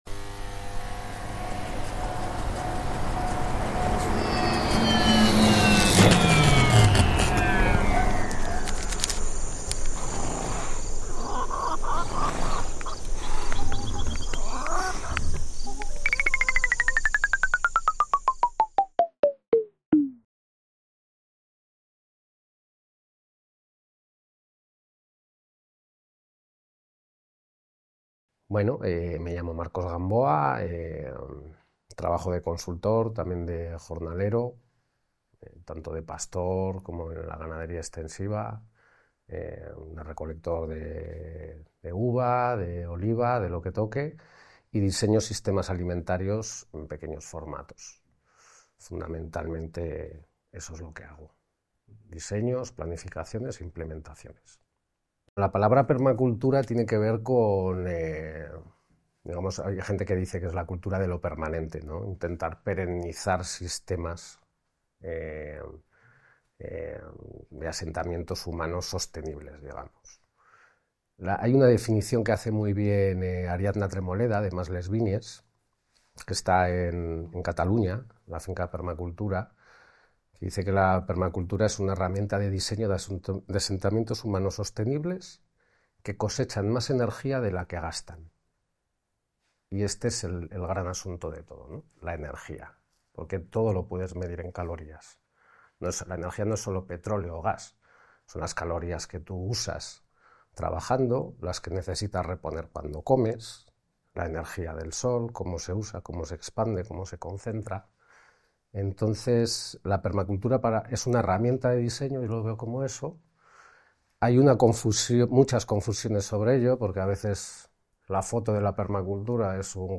En esta nueva entrevista SaD